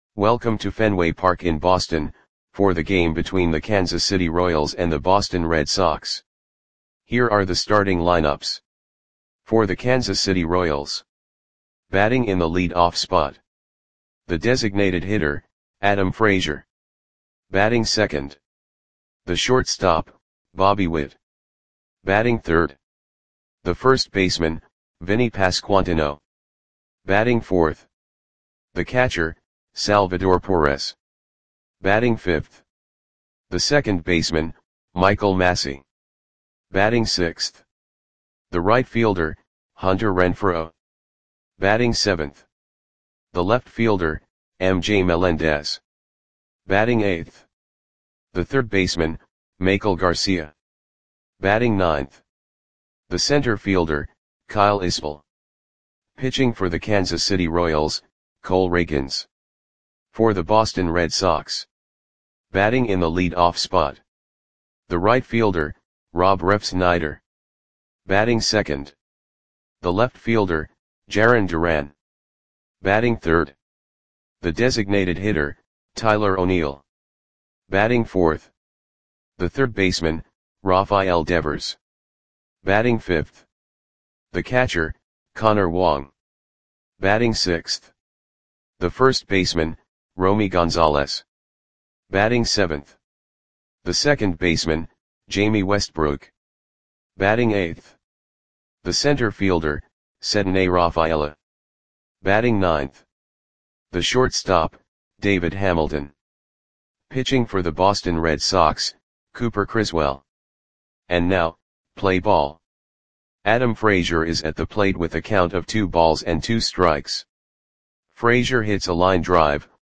Lineups for the Boston Red Sox versus Kansas City Royals baseball game on July 12, 2024 at Fenway Park (Boston, MA).
Click the button below to listen to the audio play-by-play.